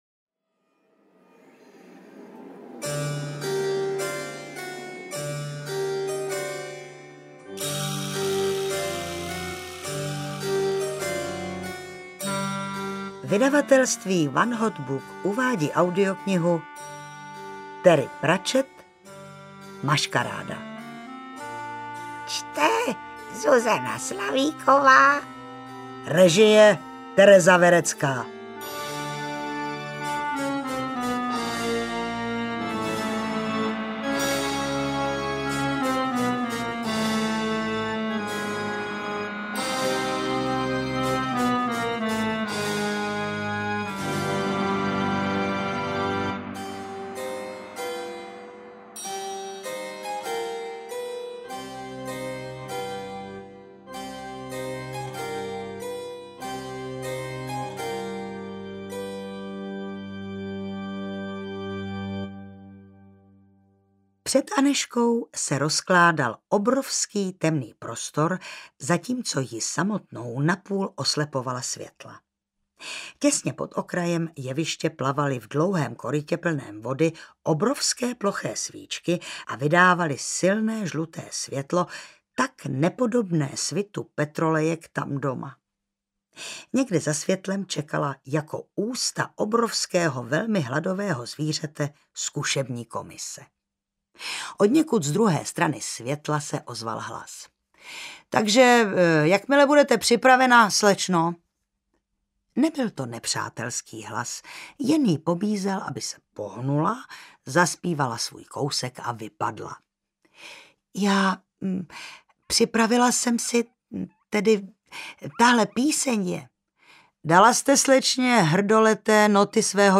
Maškaráda audiokniha
Ukázka z knihy